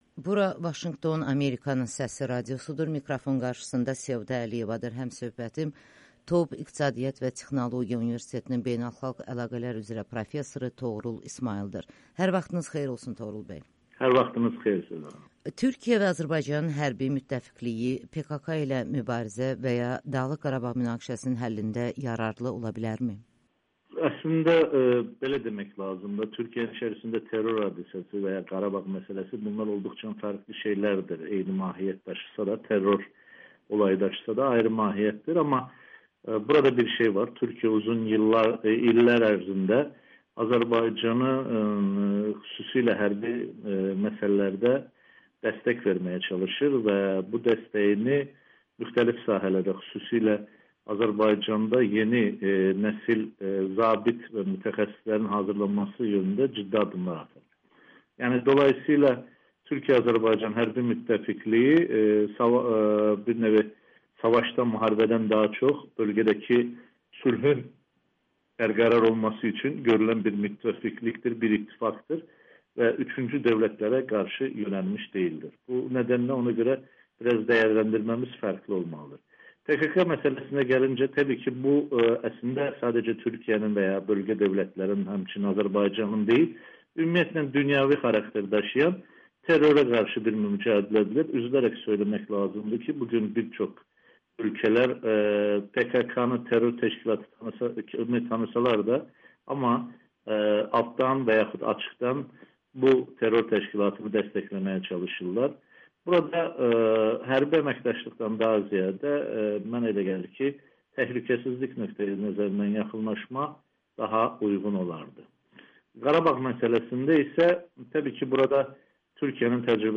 Müsahibələr